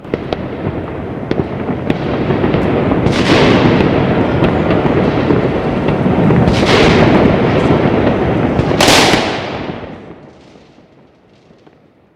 Грохот взрывов при бомбежке